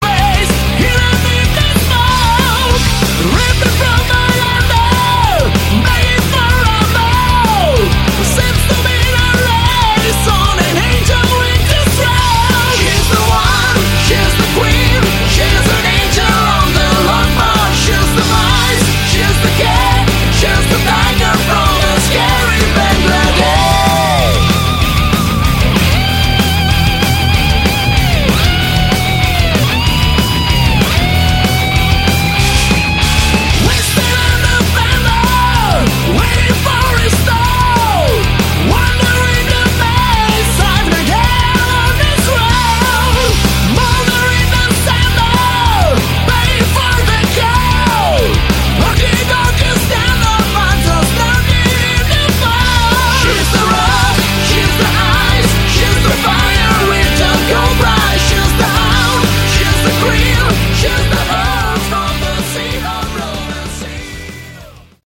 Category: Melodic Hard Rock
vocals
lead, rhythm, and acoustic guitars, keyboards
bass
drums